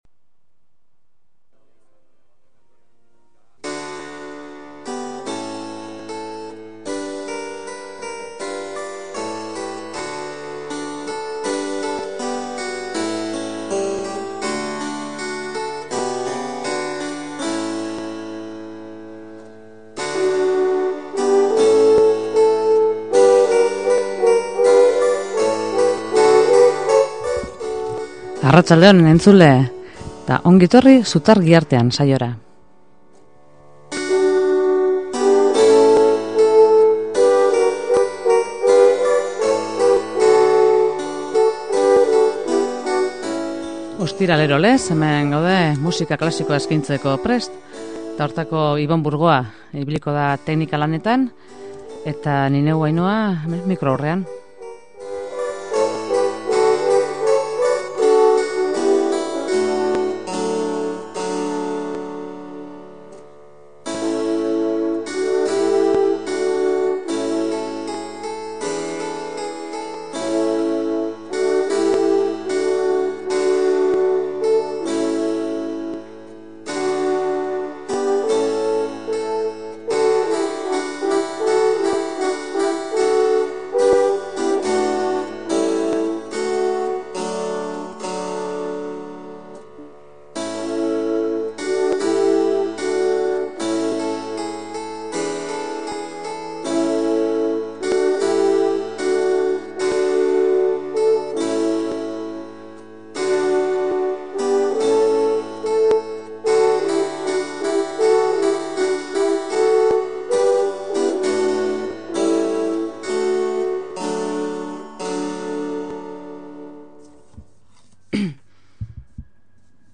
Haren musikaren azalean alaitasuna eta distira nabari dira, baina barrualdean tristura eta malenkoniaren itzala ageri da.